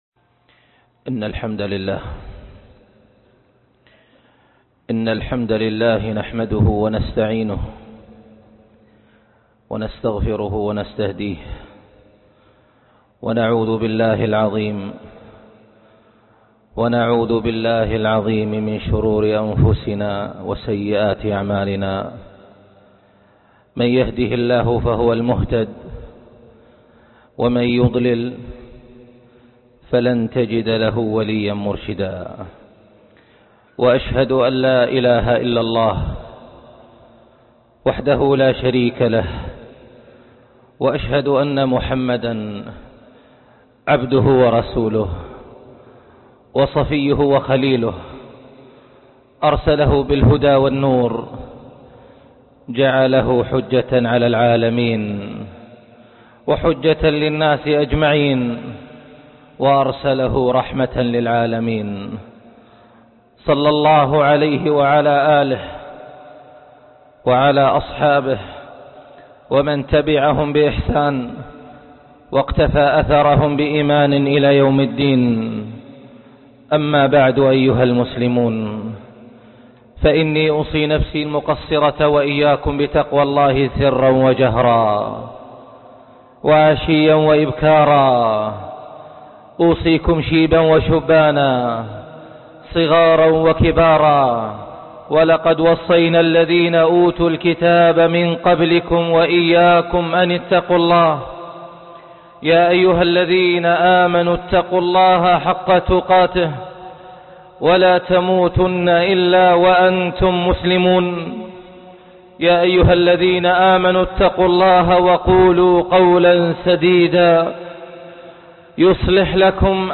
التحالف لا التخالف -خطبة الجمعة